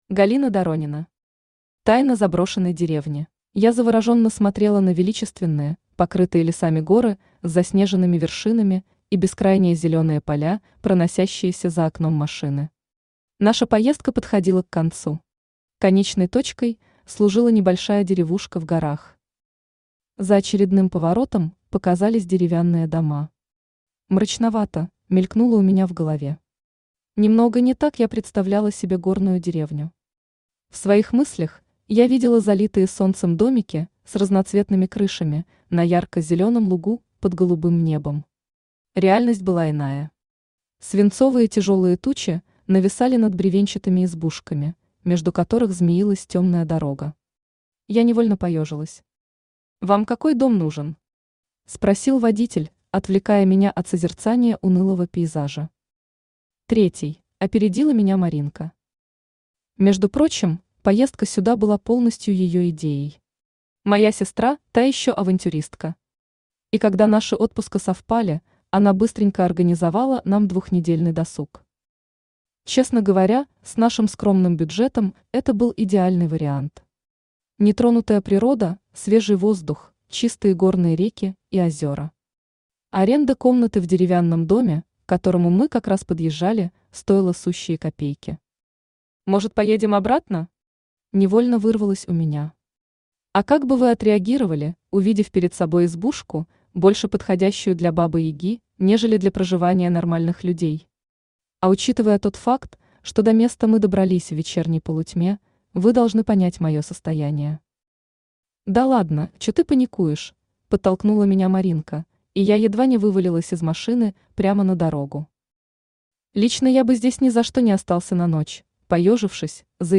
Аудиокнига Тайна заброшенной деревни | Библиотека аудиокниг
Aудиокнига Тайна заброшенной деревни Автор Галина Доронина Читает аудиокнигу Авточтец ЛитРес.